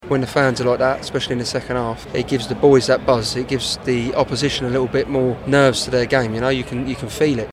AFC-WIMBLEDON-INTERVIEW-.mp3